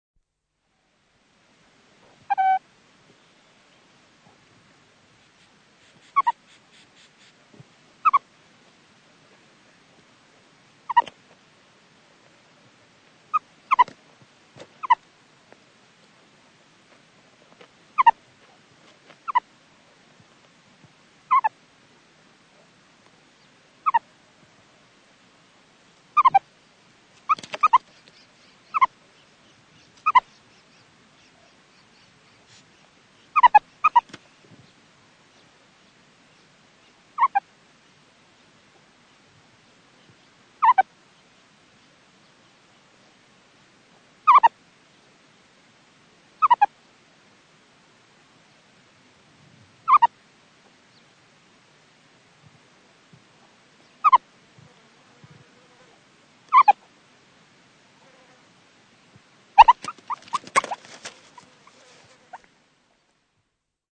Звук на земле стоит сурикат